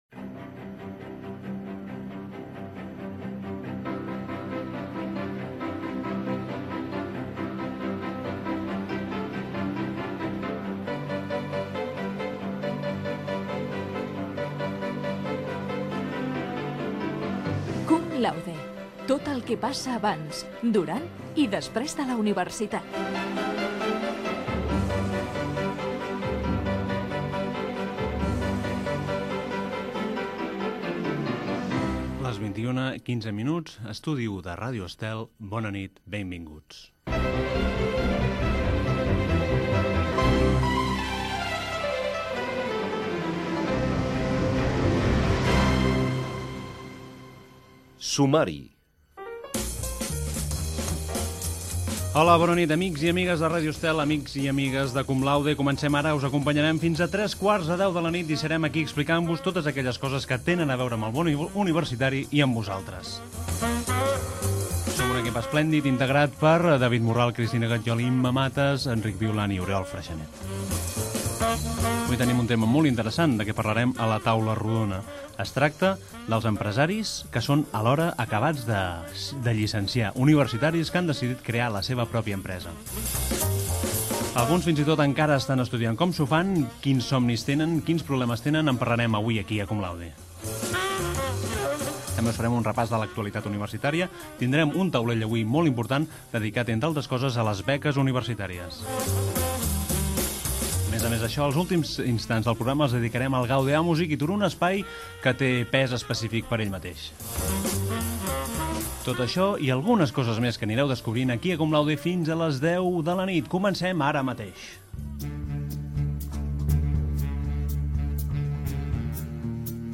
Careta del programa, hora, presentació, equip, sumari, notícies universitàires, telèfons del programa